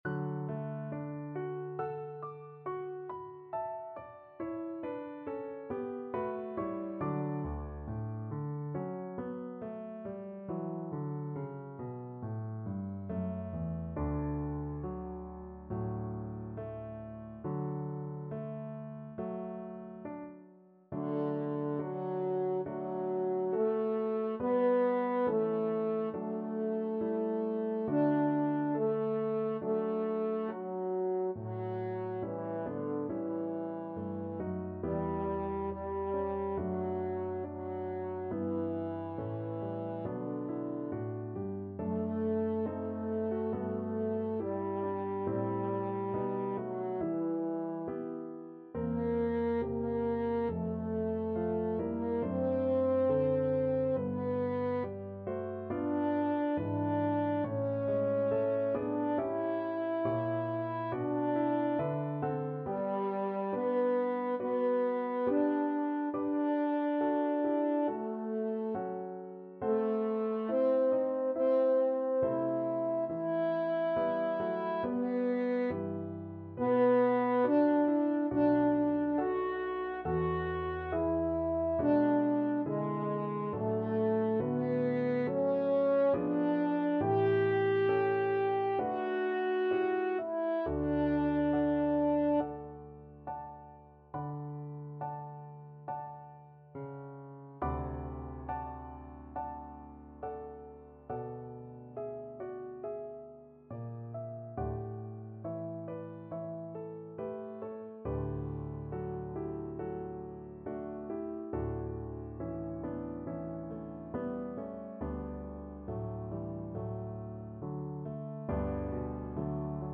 French Horn
2/2 (View more 2/2 Music)
D4-G5
Slow =c.69
D major (Sounding Pitch) A major (French Horn in F) (View more D major Music for French Horn )